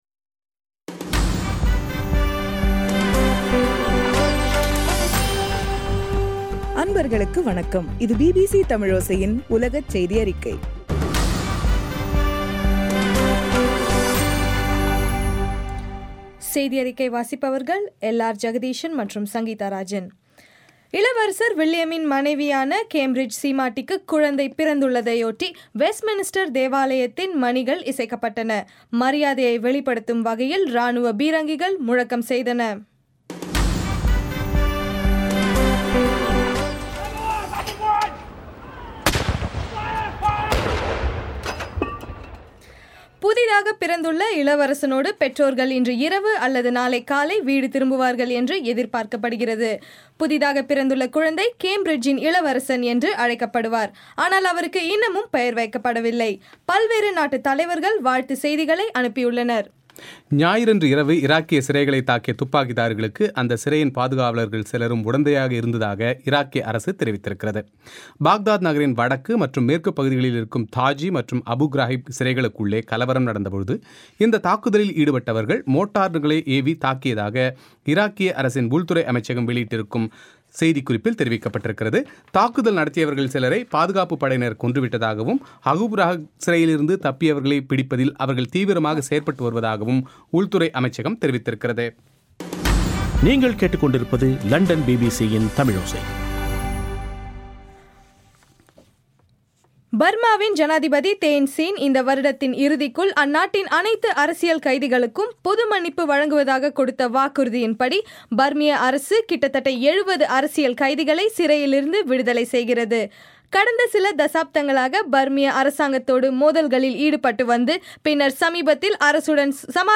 ஜூலை 23 பிபிசி தமிழோசை உலகச் செய்தி அறிக்கை